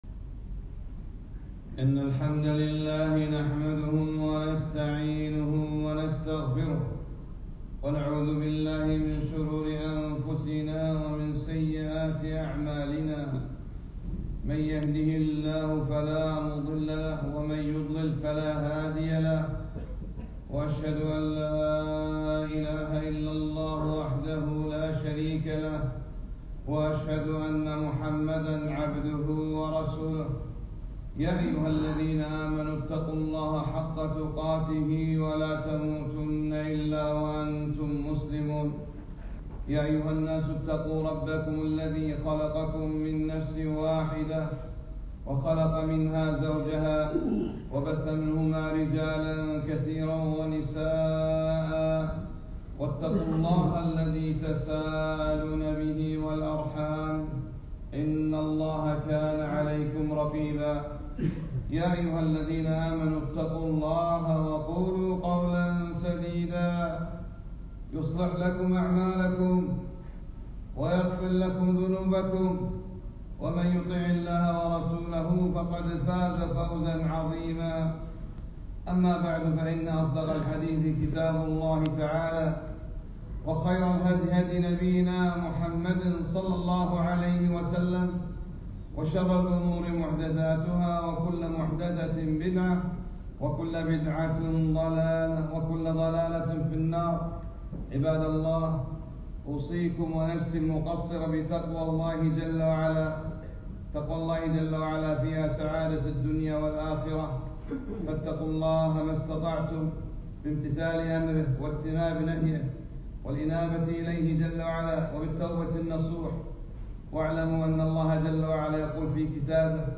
خطبة - قال تعالى :( وما كان الله معذبهم وهم يستغفرون )